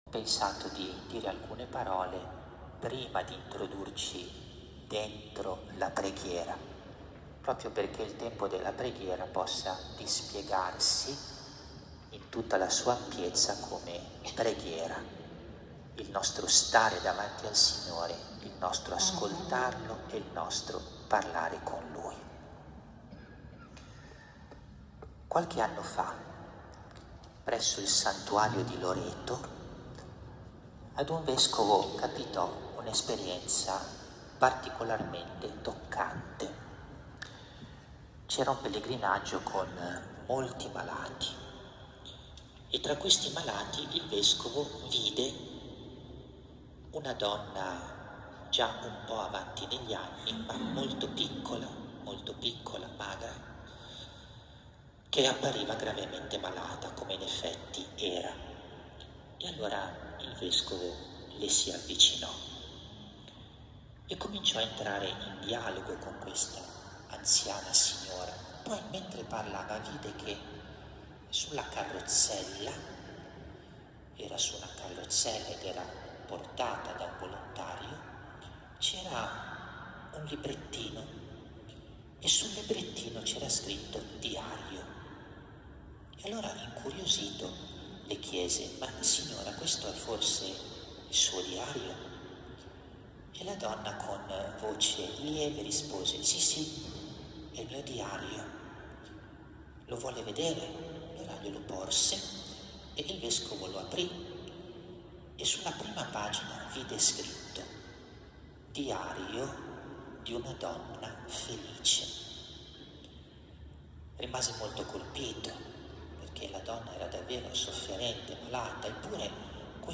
AUDIO RIFLESSIONE (Mons. Marini)